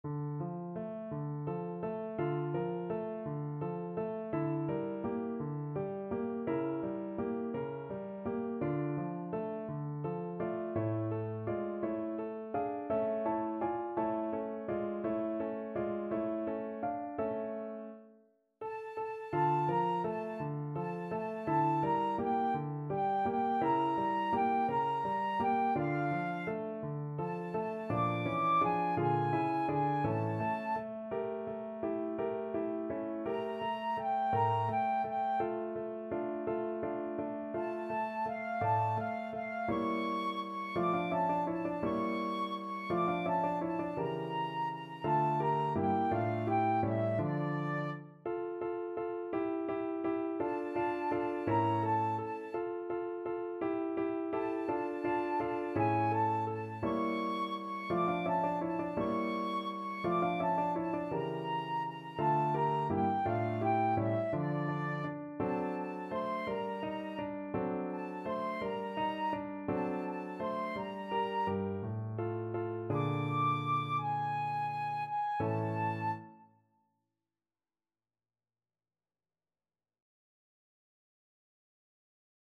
Classical Mozart, Wolfgang Amadeus L'ho perduta me meschina from from Le Nozze di Figaro Flute version
D minor (Sounding Pitch) (View more D minor Music for Flute )
. = 56 Andante
6/8 (View more 6/8 Music)
Flute  (View more Intermediate Flute Music)
Classical (View more Classical Flute Music)